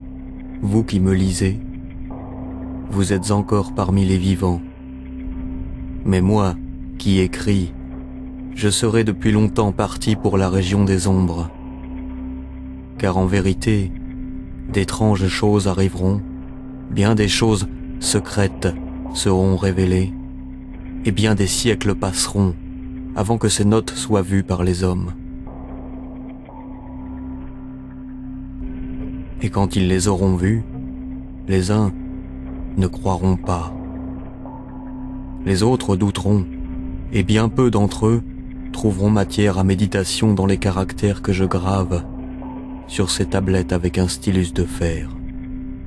Lire un extrait - Ombres de Edgar Allan Poe